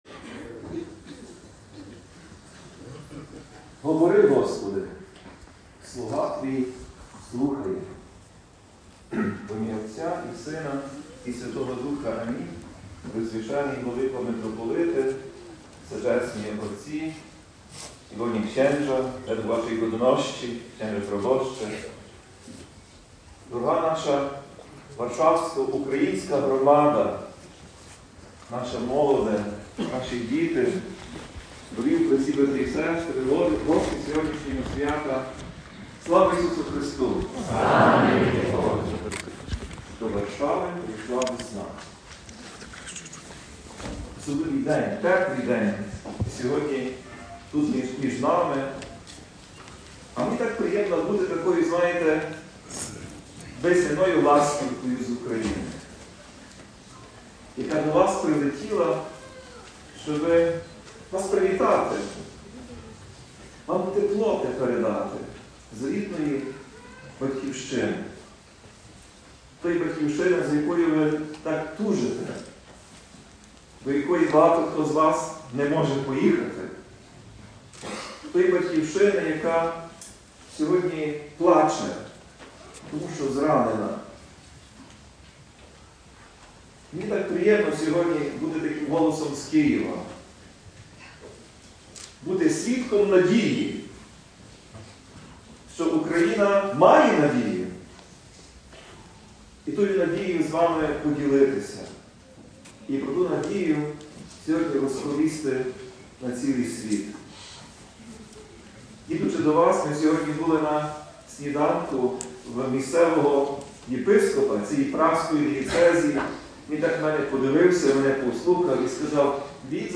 Проповідь Блаженнішого Святослава у четверту неділю Великого посту